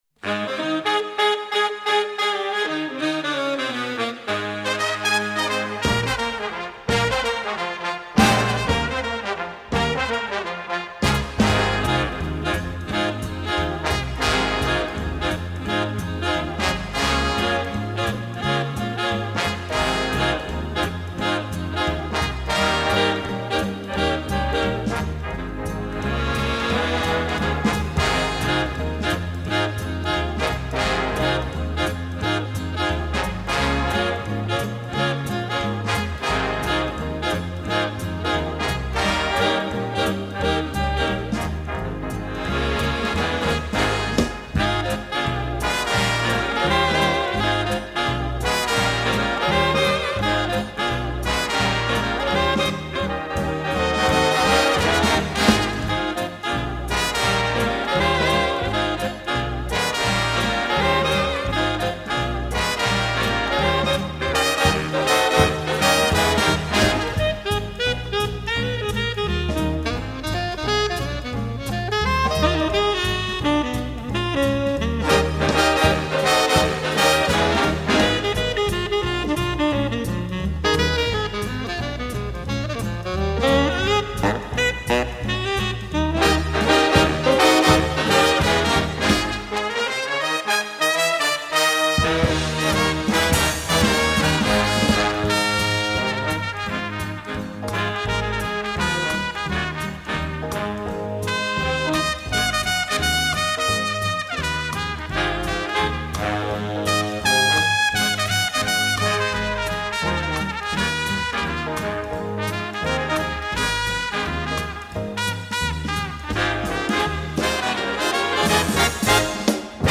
Big Band